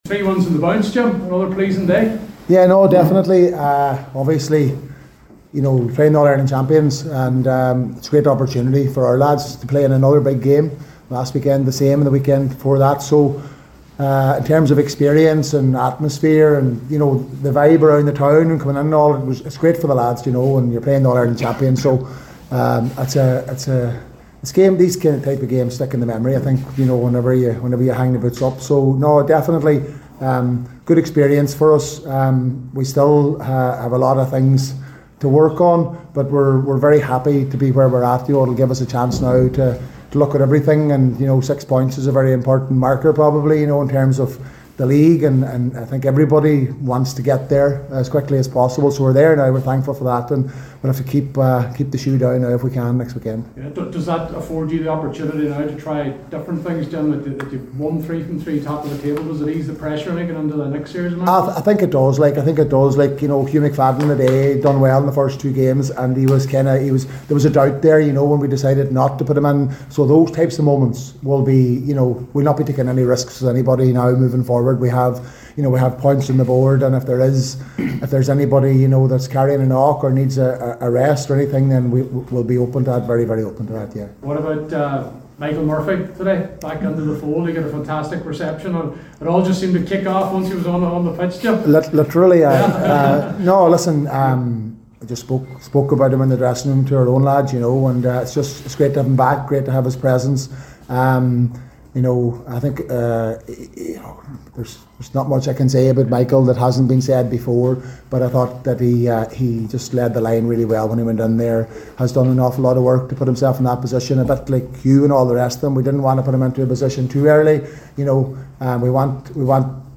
After the game, whilst speaking to the assembled press, manager Jim McGuinness said “there’s not much I can say about Michael that hasn’t been said before”. Ryan McHugh was also part of the press conference…